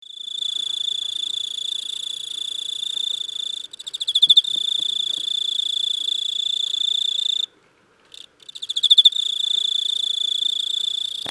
ennmakoorogi.MP3